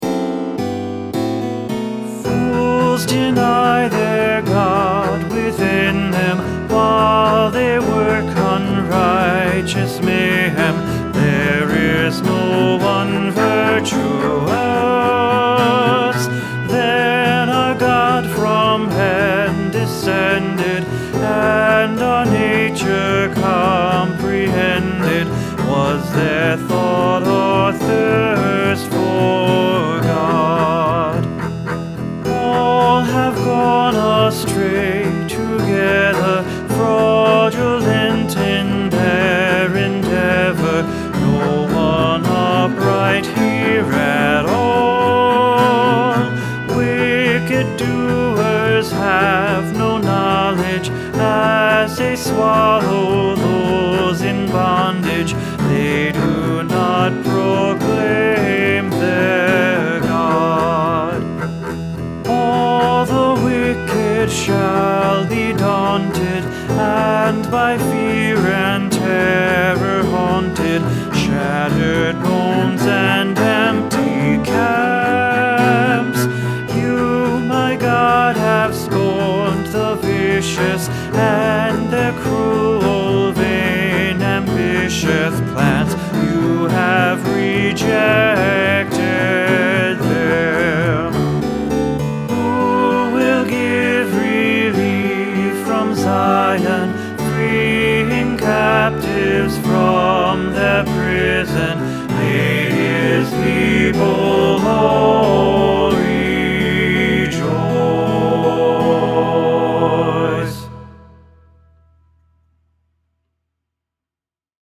Sheet music for voice and piano.